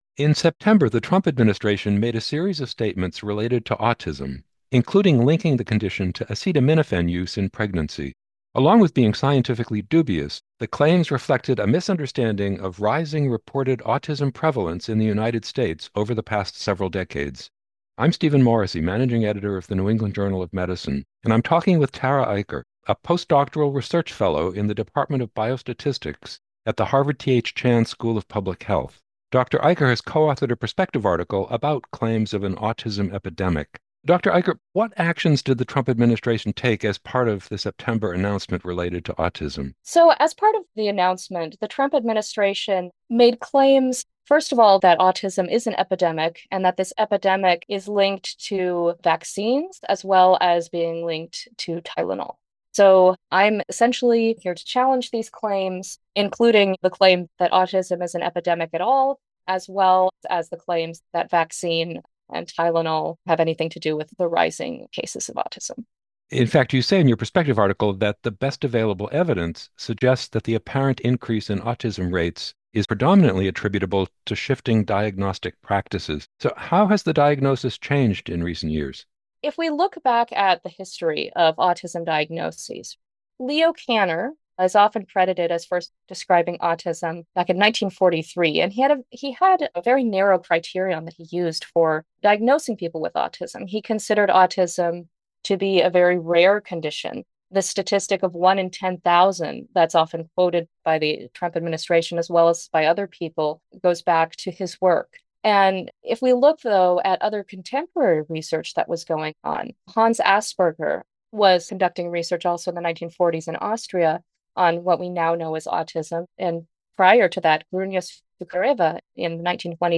NEJM Interviews NEJM Interview